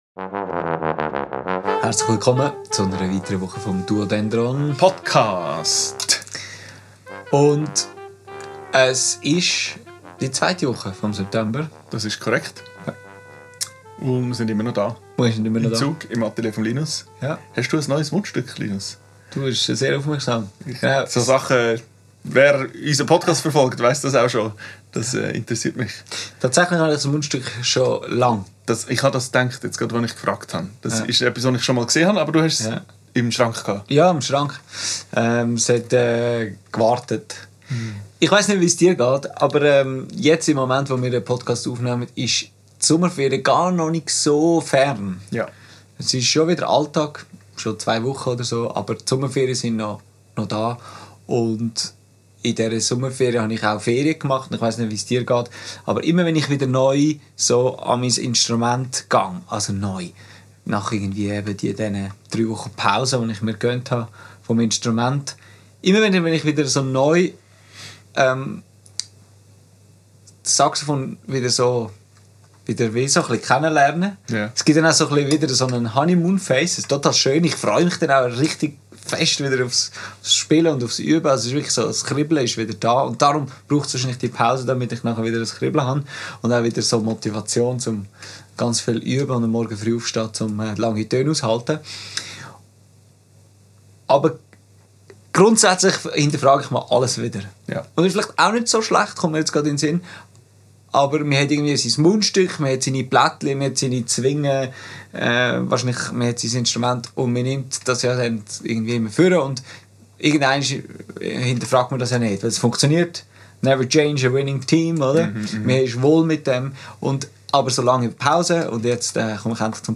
Aufgenommen am 28.08.2025 im Atelier